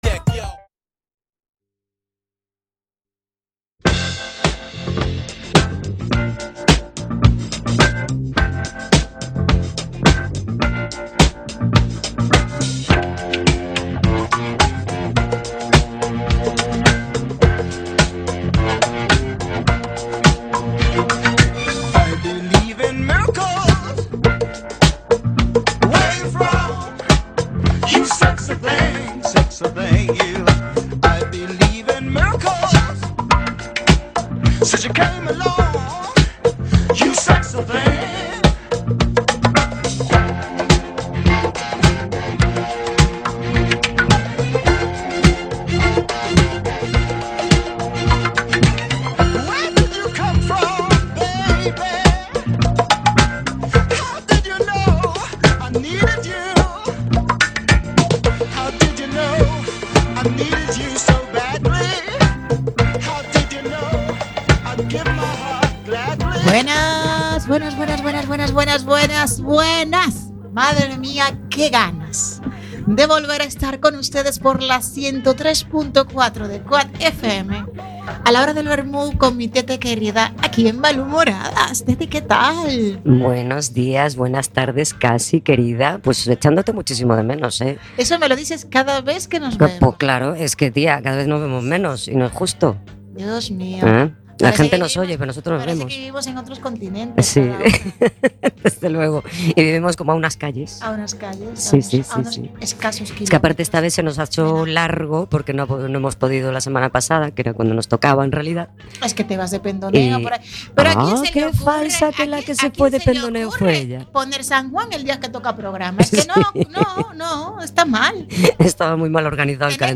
Somos un par de malhumorhadas que, en cada programa, creceremos en número y en espíritu, acompañándonos de personas que, como nosotras, se pre-ocupan y se ocupan de las cosas importantes, dando apoyo a proyectos y movimientos sociales con diferentes causas y objetivos. Todo esto con mucho humor (bueno y malo) y con mucha música y diversión, todos los miércoles a las 20:00 en Cuac FM (redifusión: sábados 10:00) y los jueves a las 14:00 en OMC Radio.